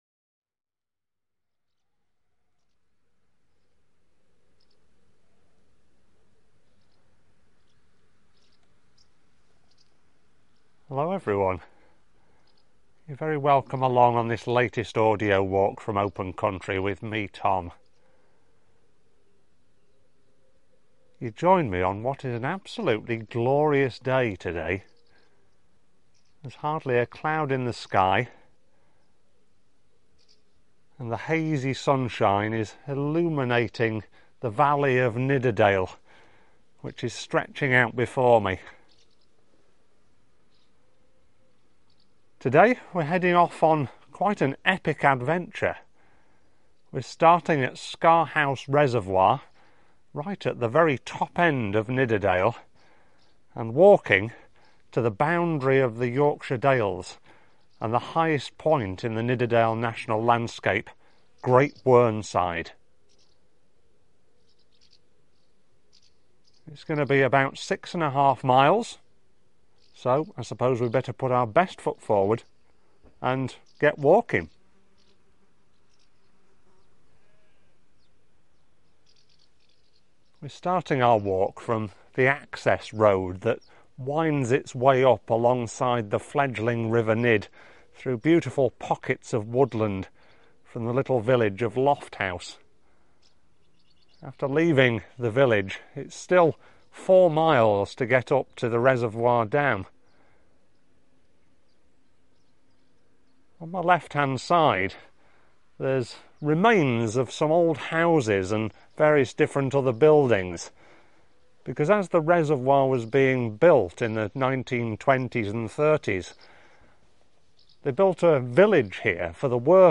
This audio trail accompanies: Scar House Reservoir
Scar-House-Great-Whernside-Audio-Walk.mp3